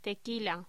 Locución: Tequila
voz